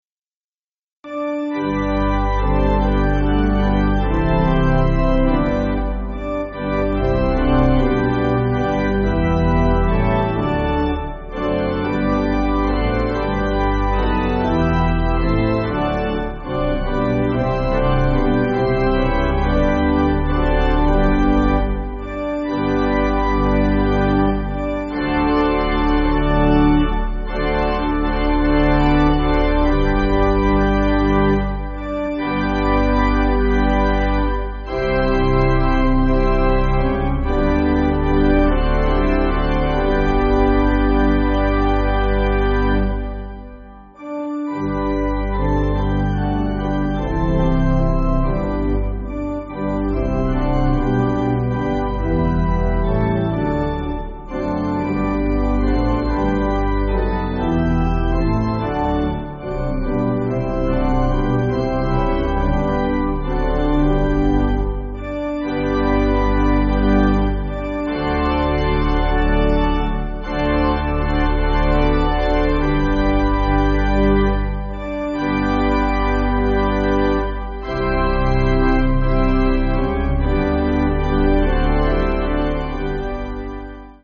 (CM)   4/G